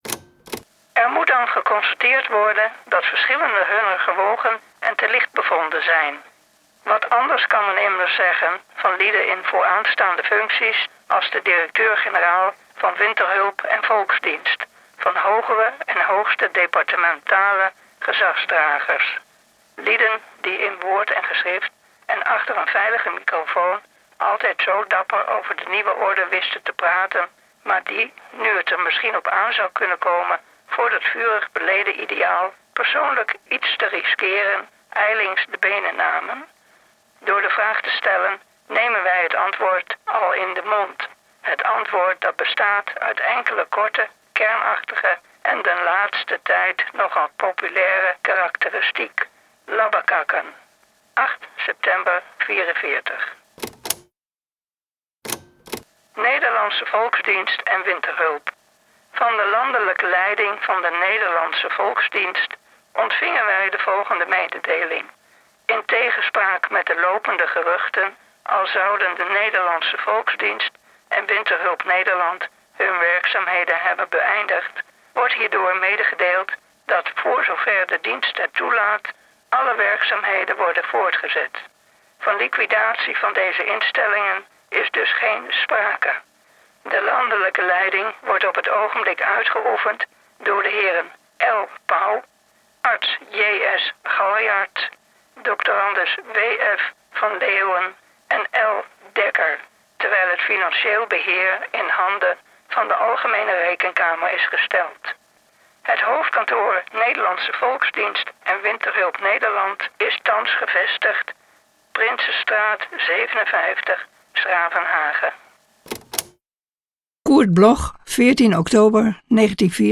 voorgedragen door